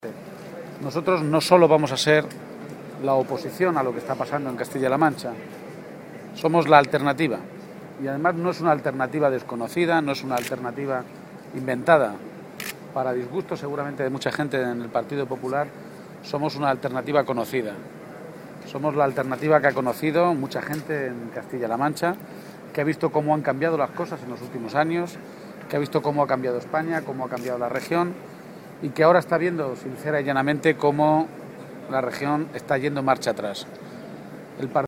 García-Page atiende a los medios de comunicación.
El secretario general del PSOE de Castilla-La Mancha, Emiliano García-Page, clausuró el Congreso provincial de los socialistas albaceteños
Garcia_Page-congreso_PSOE_AB-1.mp3